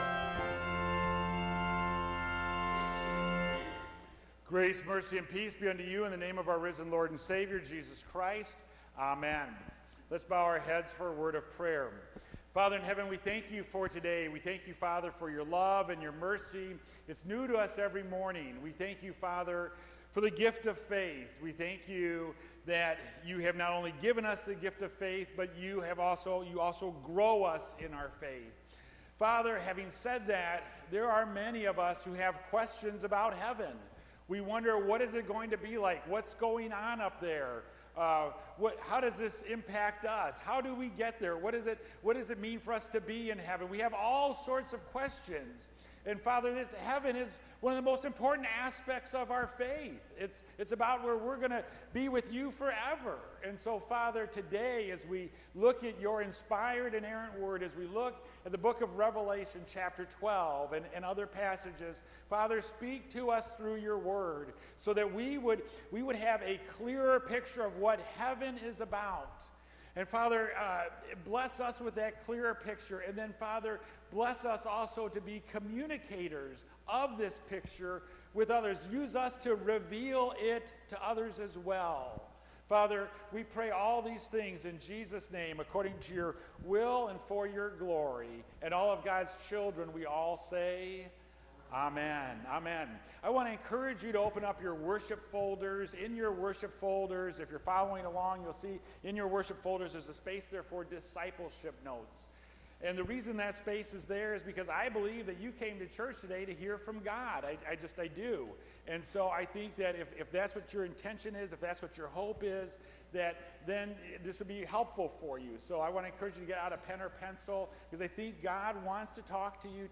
Current-sermon.mp3